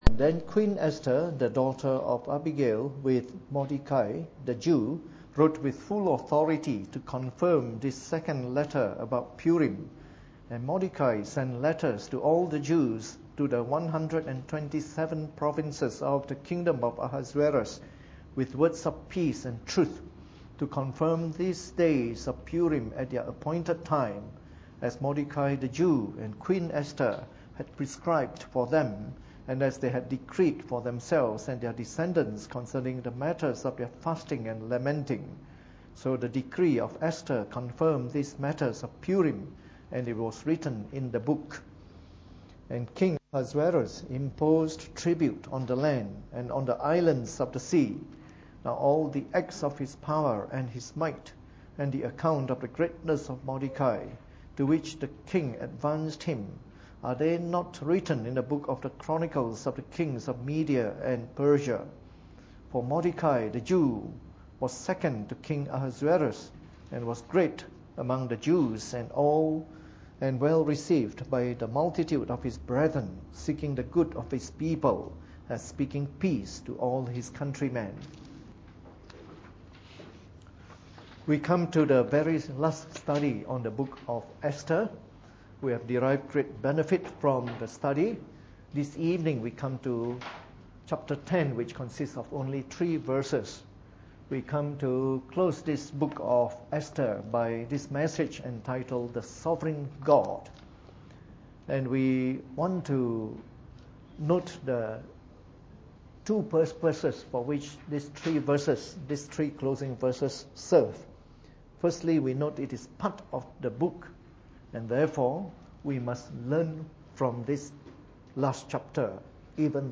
Preached on the 11th of December 2013 during the Bible Study, the final study in our series of talks on the Book of Esther.